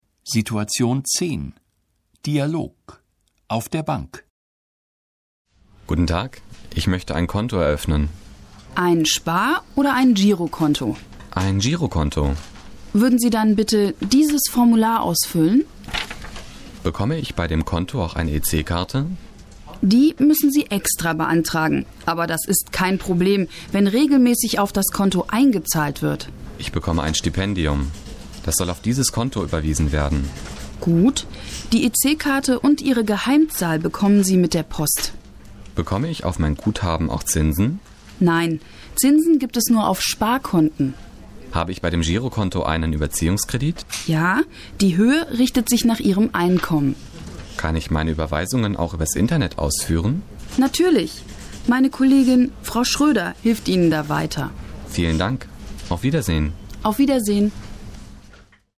Situation 10 – Dialog: Auf der Bank (940.0K)